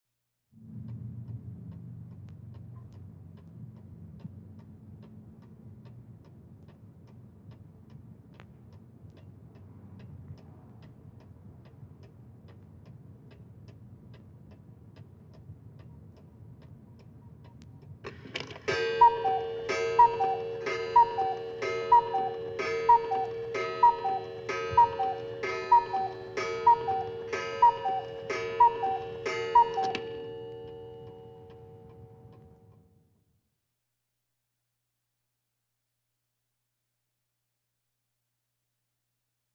100 Sound Effects Vol 1 - 27 - Cuckoo Clock - Madacy Music Group Inc.